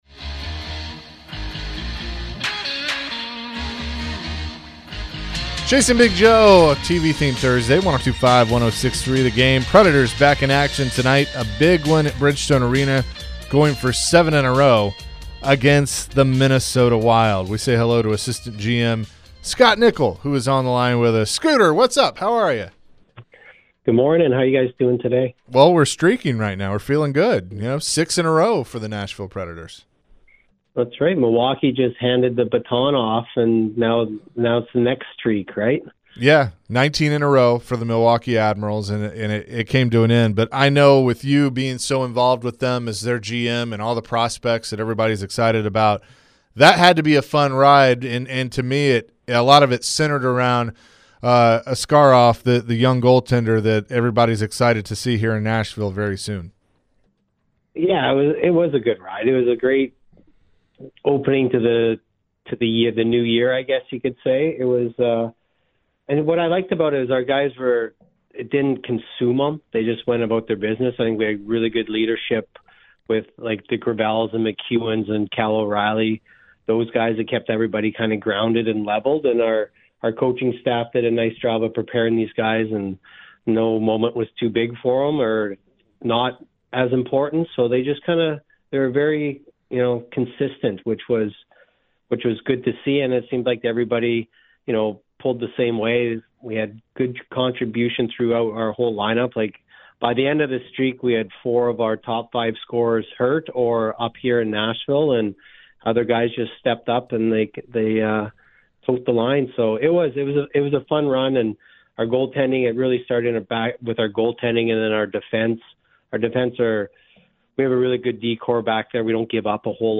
Scott Nichol Interview (02-29-24)
Milwaukee Admirals' General Manager Scott Nichol joins the show. Nichol talks about the team's 19-game win streak, and how goalie Yaroslav Askarov played a huge role during the run.